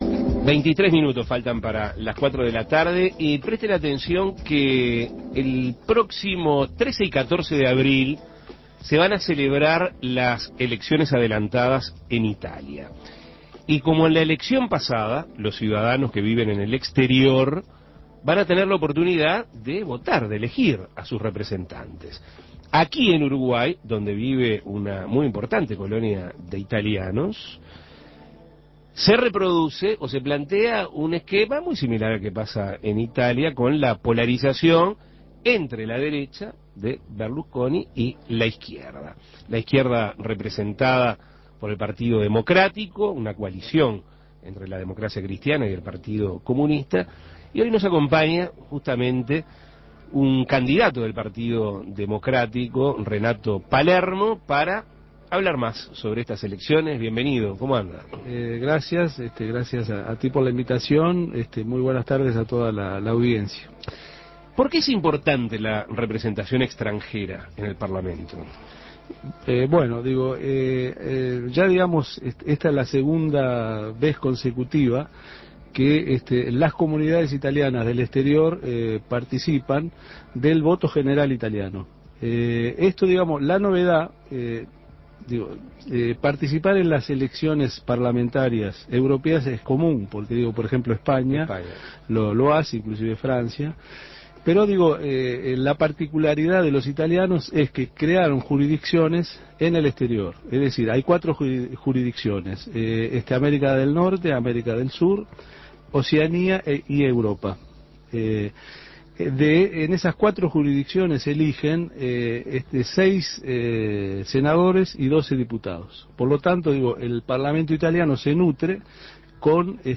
Entrevistas Elecciones en Italia Imprimir A- A A+ El próximo 13 y 14 de abril se celebrarán las elecciones anticipadas en Italia.